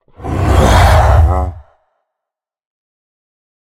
minecraft / sounds / mob / ravager / roar2.ogg
roar2.ogg